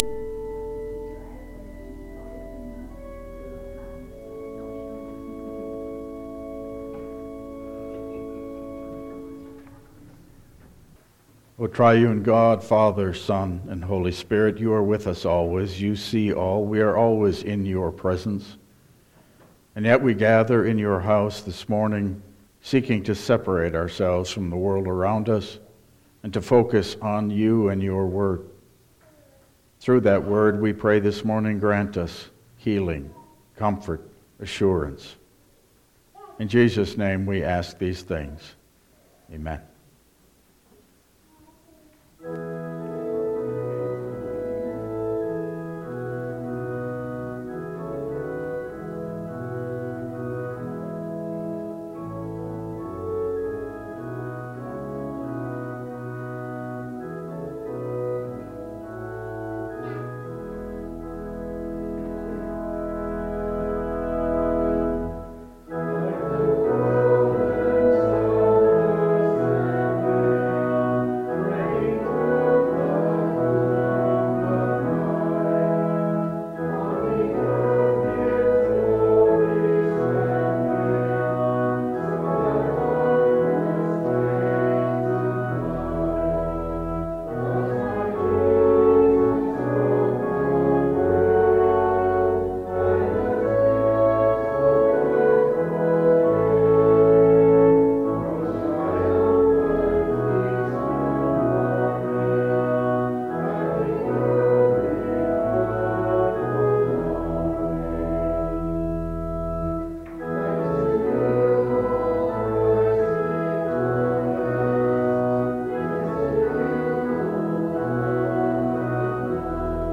Download Files Printed Sermon and Bulletin
Service Type: Regular Service